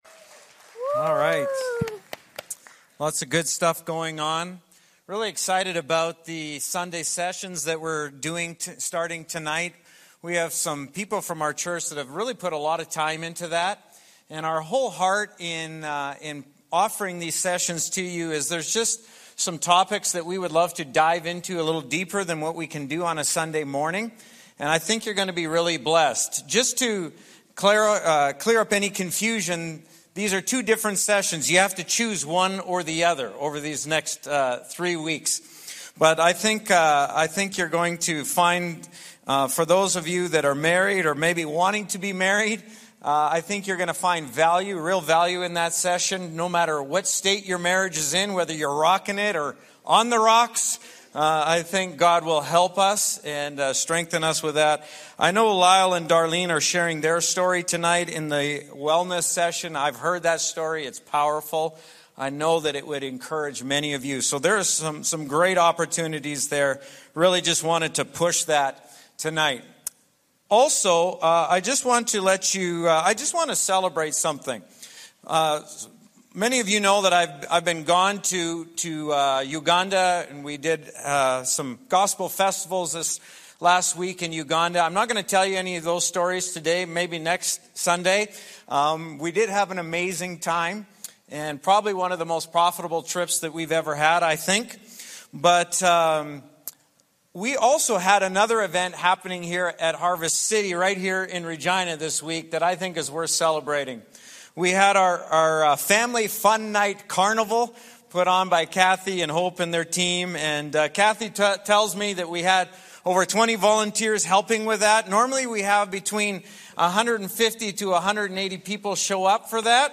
Father’s Day Panel Discussion